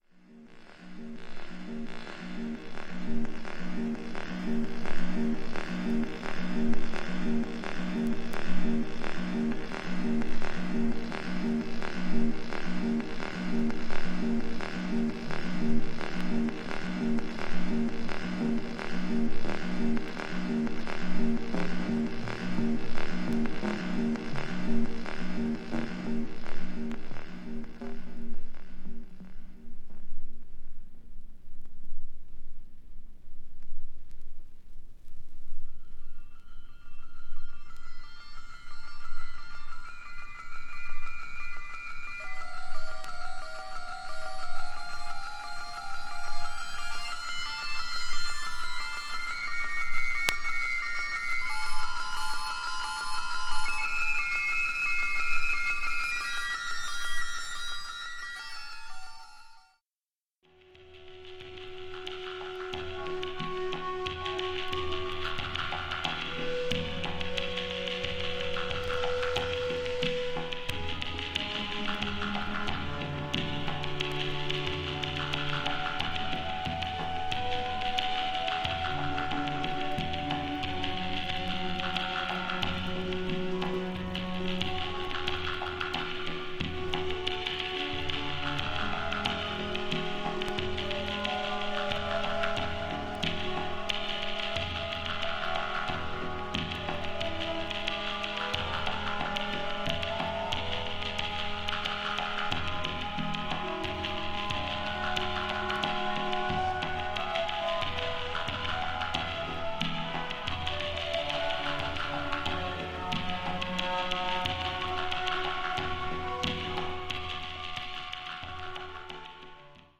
ドローンとシンセを使った独特の世界観が見事にハマったKANSAS CITYで育った